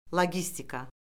log i stika